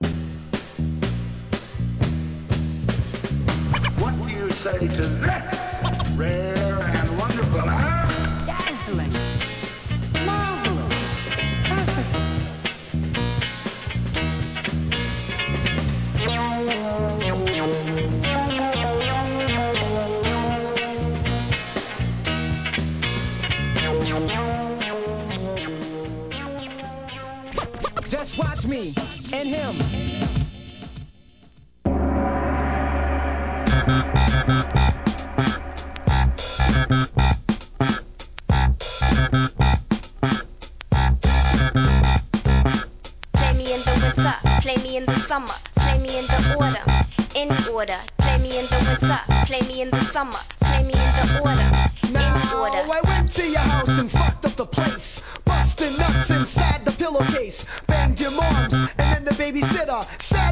HIP HOP/R&B
1999年、ボストン産アングラ!!